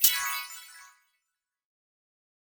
Special & Powerup (22).wav